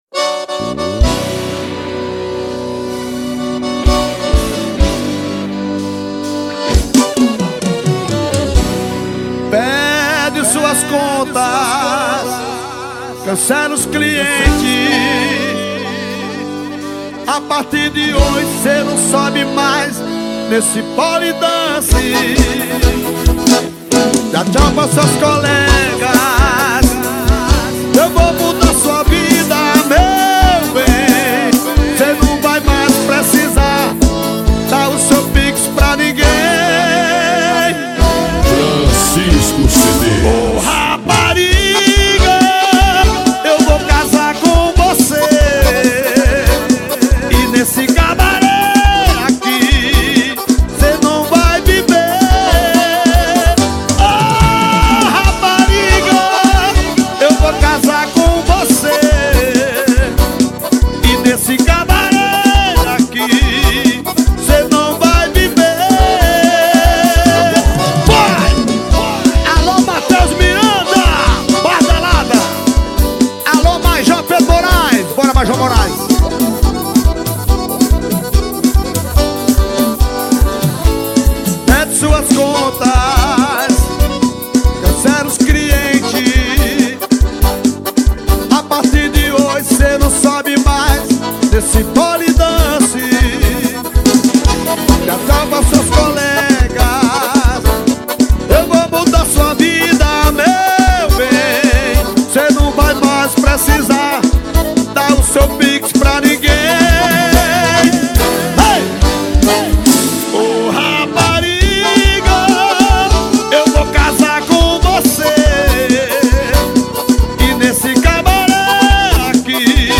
2024-07-29 09:50:15 Gênero: Forró Views